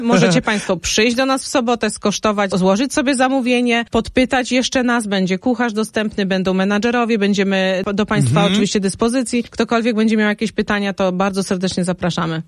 Jak powinien wyglądać świąteczny stół, mówi w naszym studiu